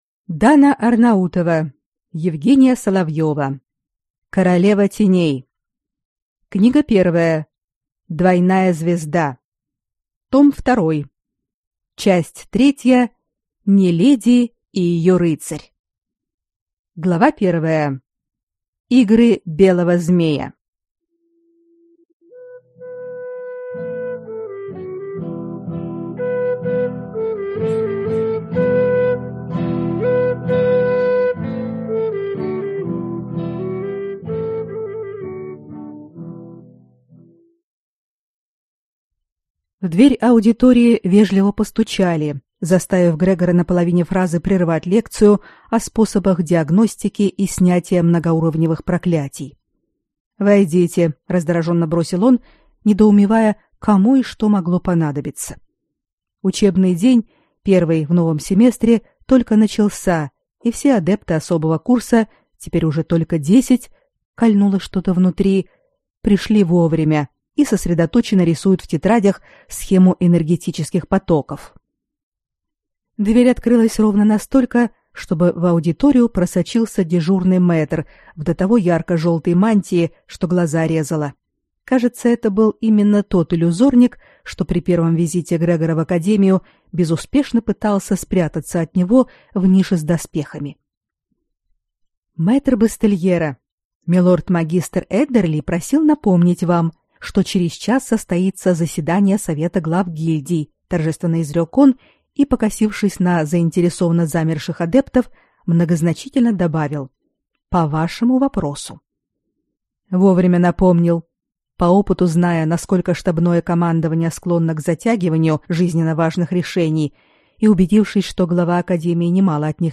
Аудиокнига Двойная звезда. Том 2 | Библиотека аудиокниг